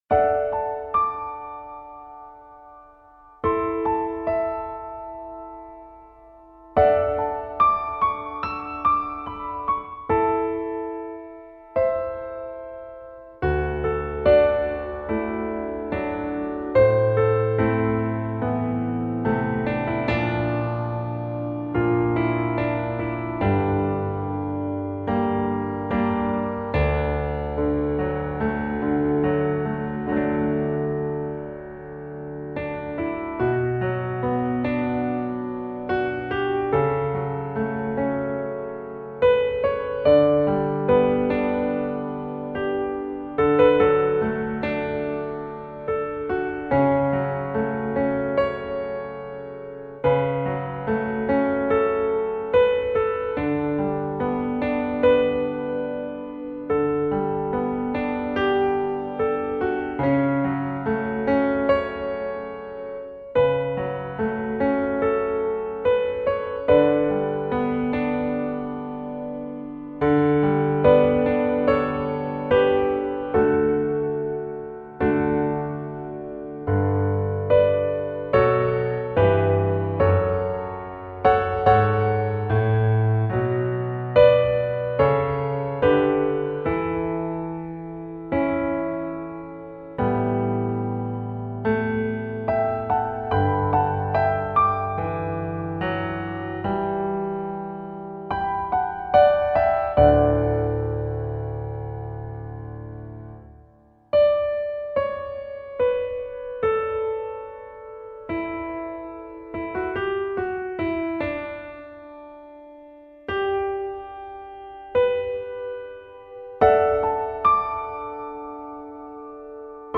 Piano Solo - Late Beginner